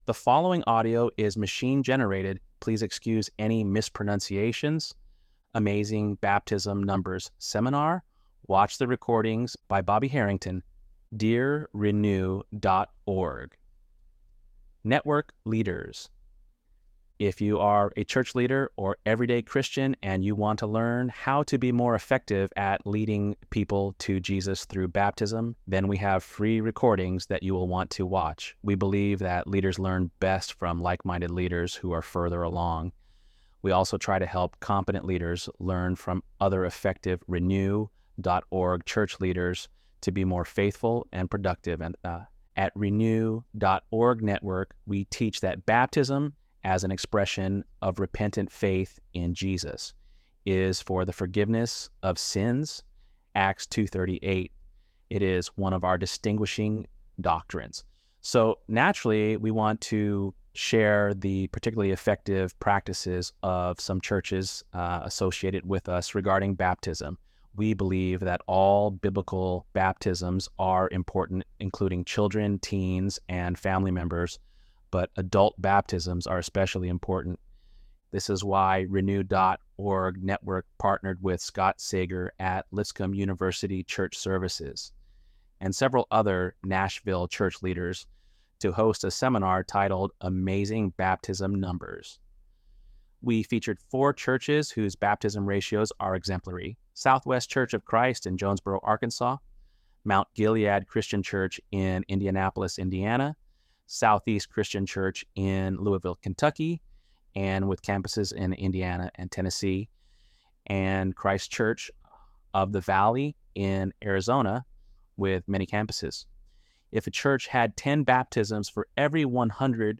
ElevenLabs_Untitled_project-38.mp3